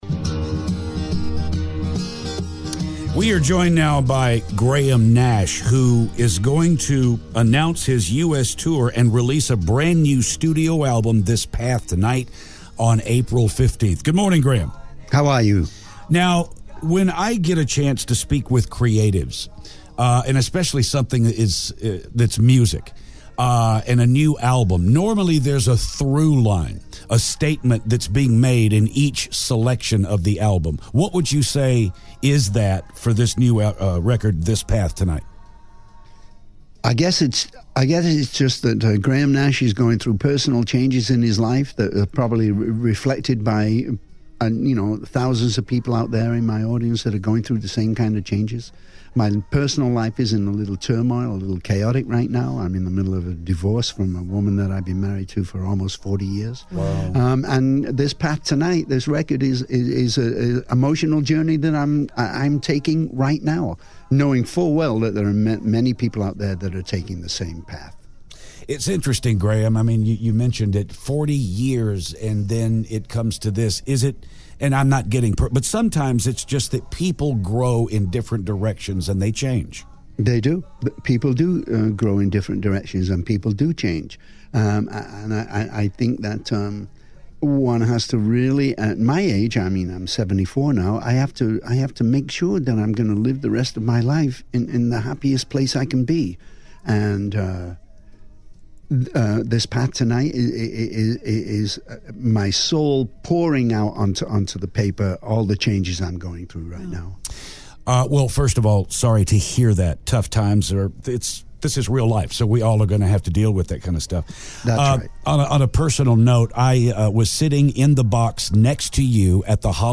Graham Nash Interview